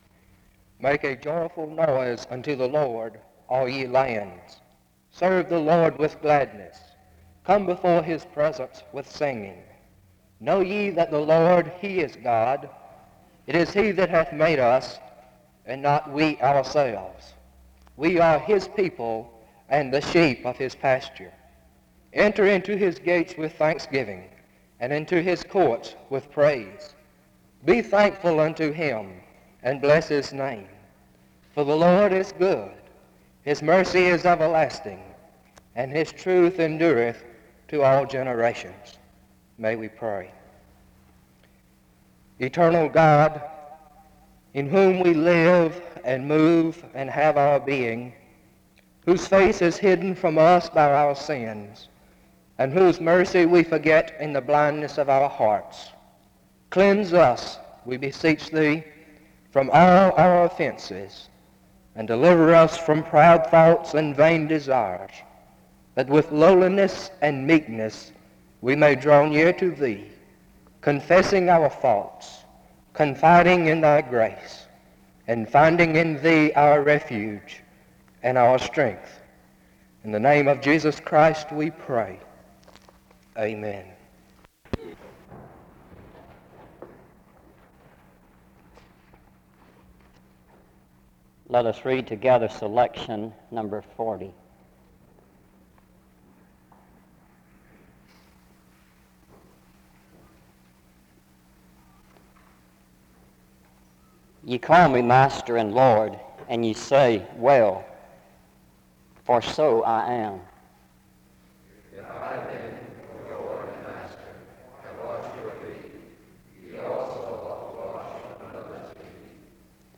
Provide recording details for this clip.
Afterwards, the service is closed in benediction and prayer (12:57-15:07).